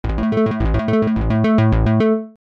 呼び出し音
フリー音源効果音「呼び出し音」です。